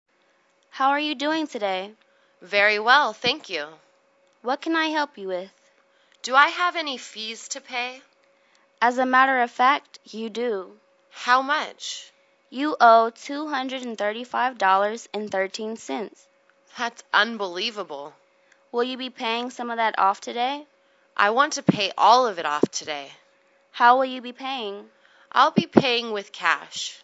银行英语对话-Paying Your Fees(1) 听力文件下载—在线英语听力室